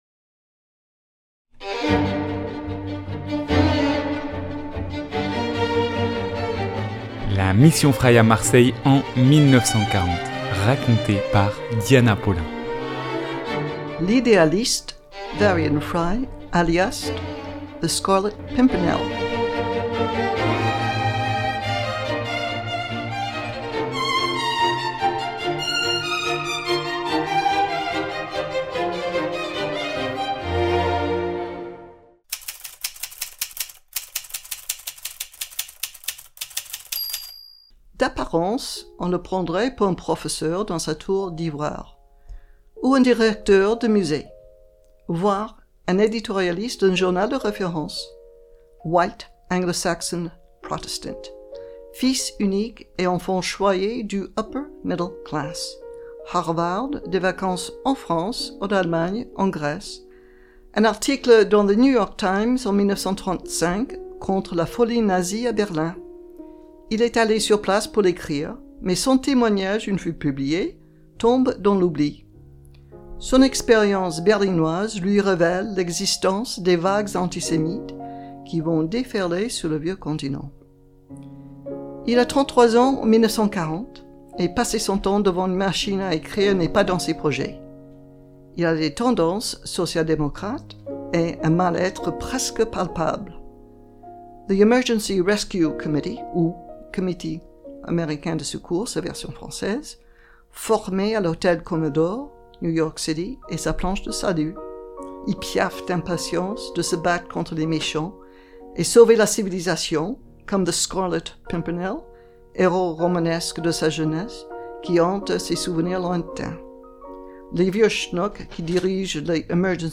3 - L'idéaliste Varian Fry alias the scarlet pimpernel (version piano).mp3 (3.25 Mo)